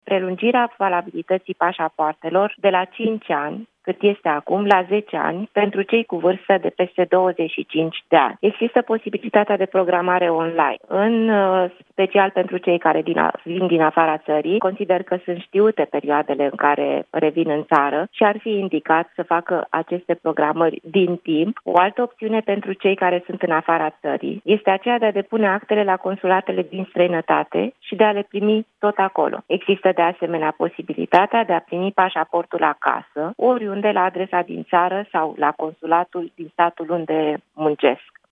În plus, ministrul Carmen Dan, reamintește la Europa FM că pentru a evita cozile de ghișee, românii se pot programa, din timp, online.